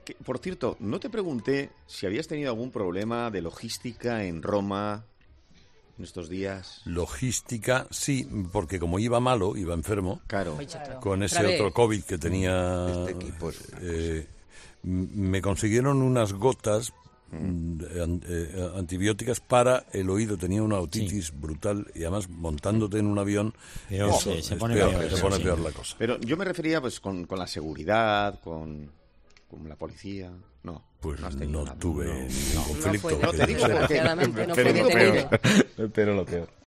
Carlos Herrera cuenta los detalles del problema de salud que tuvo en su viaje a Roma en enero de 2023